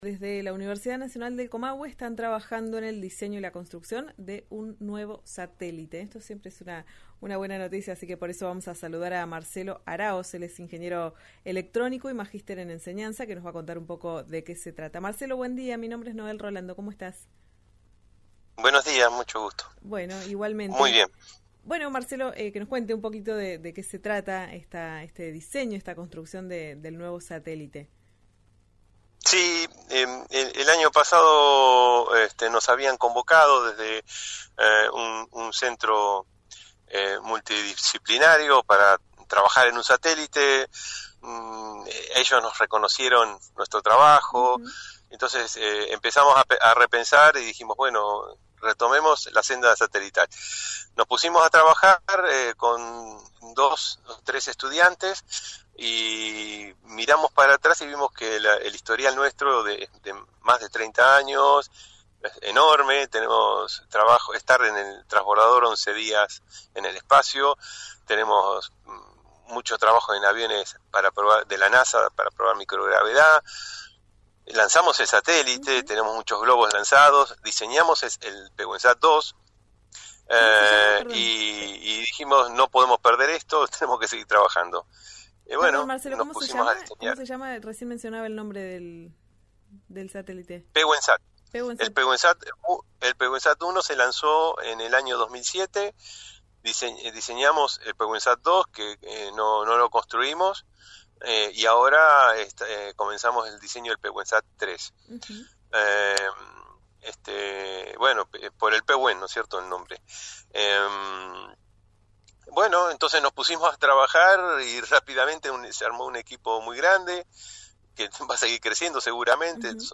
ingeniero electrónico, en RÍO NEGRO RADIO